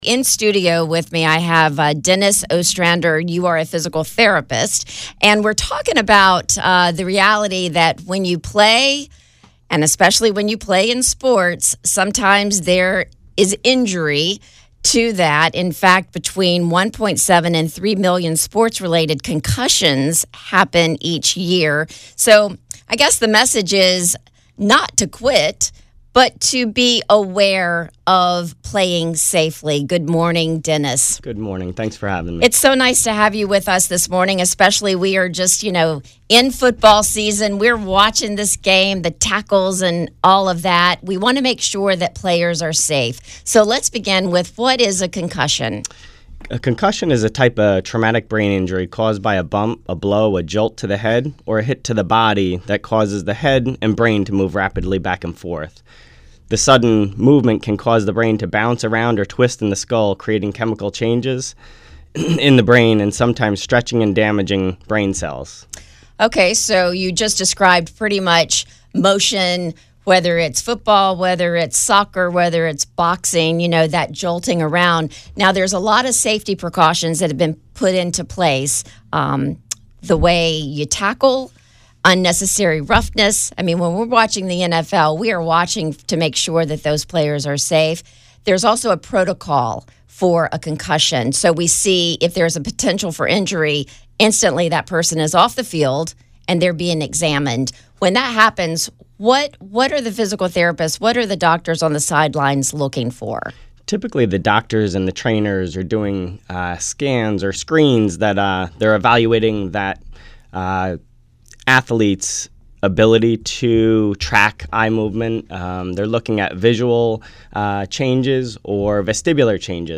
Physical Therapist and concussion expert